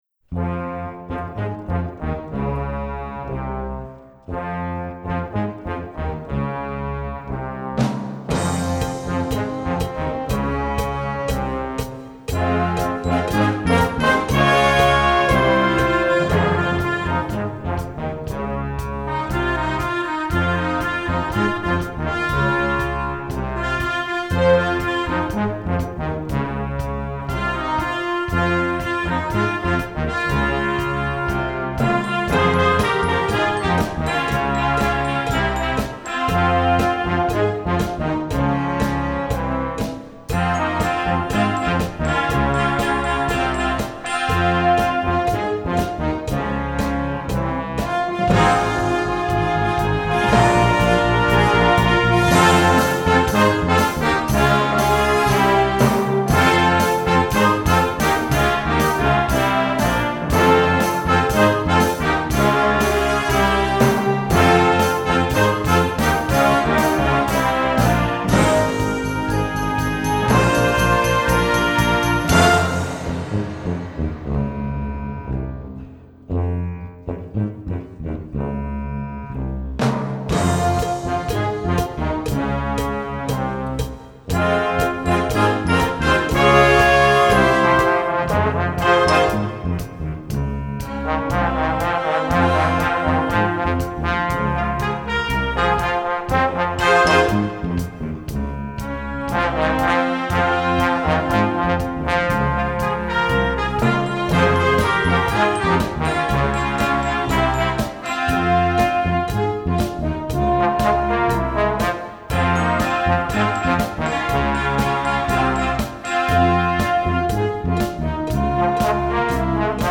Flexible Ensembles → Flex Band